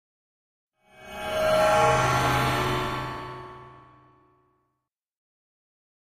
Metallic Reverse Hit Impact 3 - Mystic Show Up